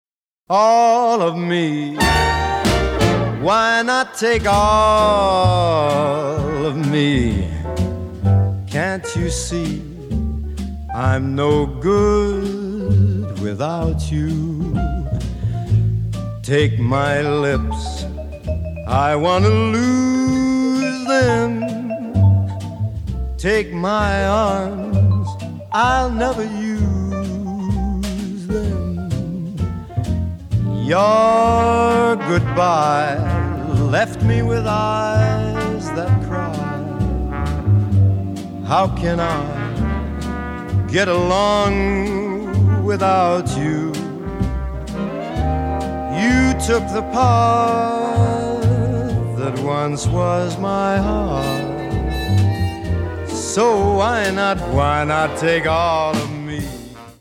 мужской вокал
пианино
Саксофон
ретро
джаз